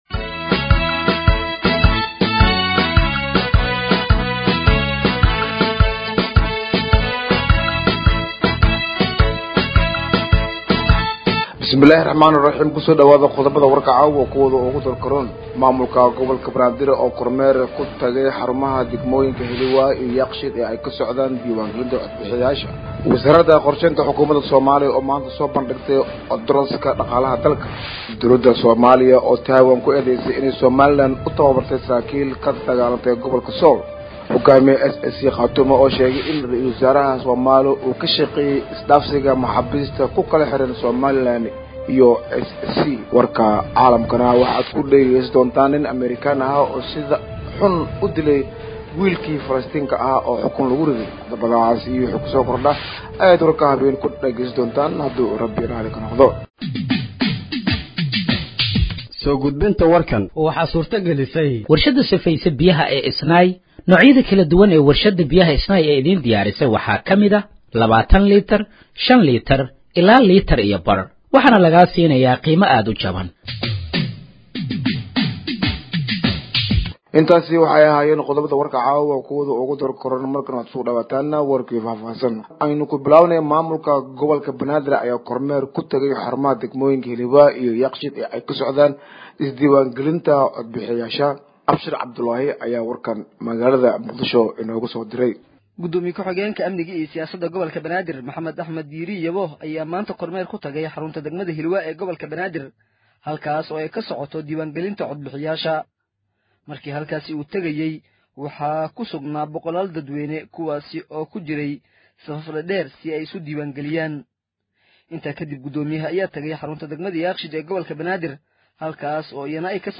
Dhageeyso Warka Habeenimo ee Radiojowhar 03/05/2025